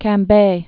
(kăm-bā), Gulf of